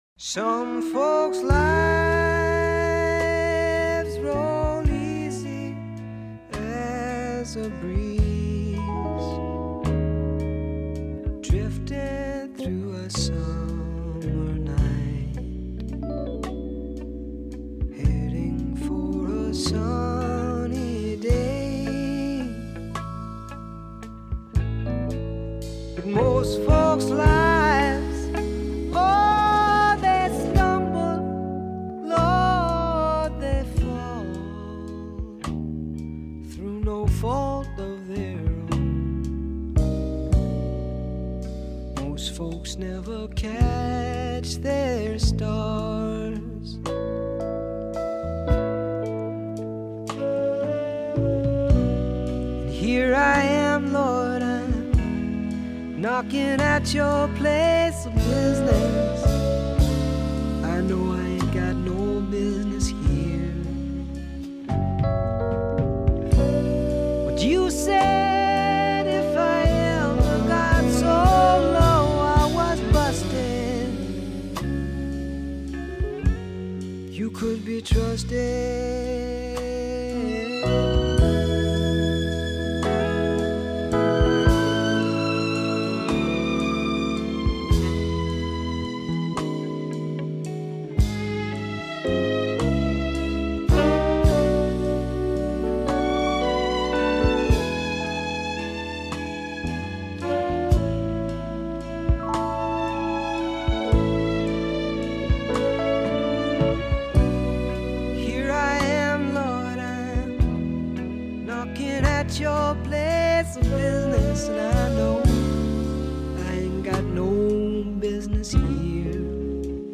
A quiet, intimate record